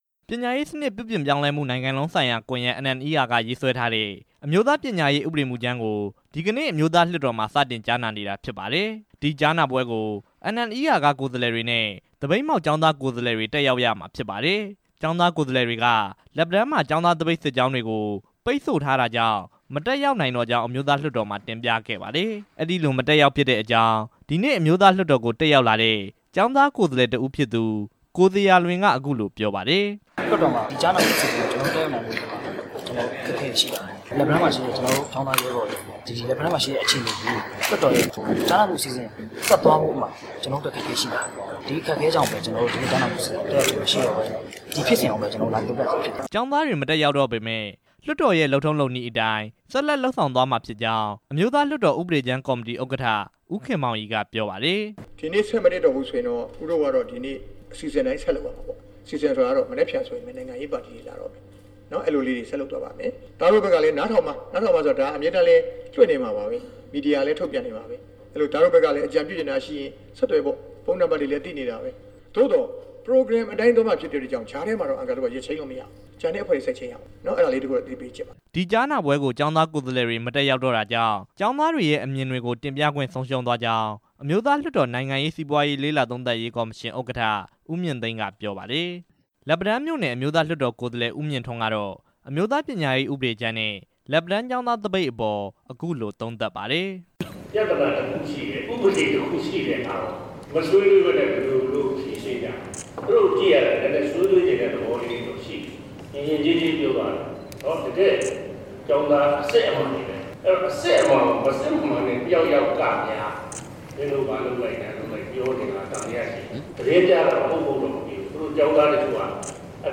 ကျောင်းသားတွေ မတက်ရောက်တော့ပေမယ့် လွှတ်တော်ရဲ့လုပ်နည်းအတိုင်း ဆက်လုပ်သွားရမှာဖြစ်ကြောင်း အမျိုးသားလွှတ်တော်ဥပဒေကြမ်းကော်မတီဥက္ကဌ ဦးခင်မောင်ရီ ကပြောပါတယ်။
လက်ပံတန်းမြို့နယ် အမျိုးသားလွှတ်တော် ကိုယ်စားလှယ်ဦးမြင့်ထွန်း ကတော့ အမျိုးသားပညာရေး ဥပဒေကြမ်းနဲ့ လက်ပတန်းကျောင်းသားသပိတ်အပေါ် အခုလို သုံးသပ်ပါတယ်။